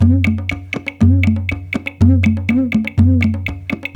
120 -CONG03R.wav